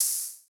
ENE Open Hat.wav